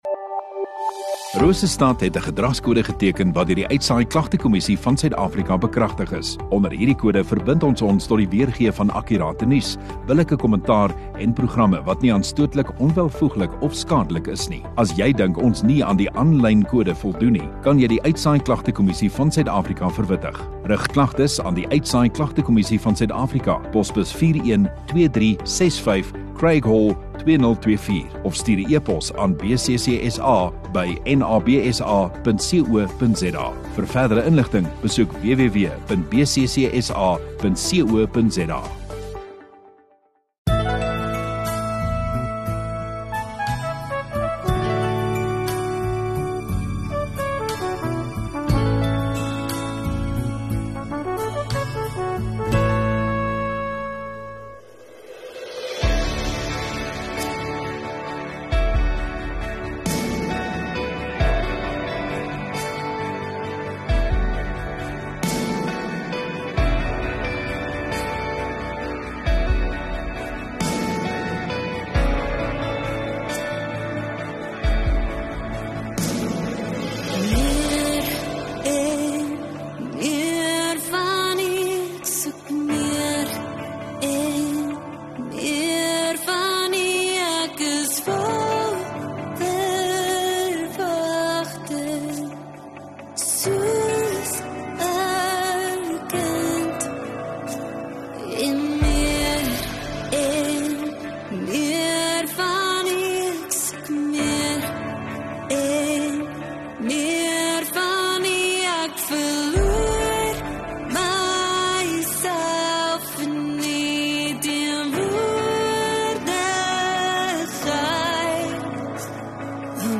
15 Jun Sondagoggend Erediens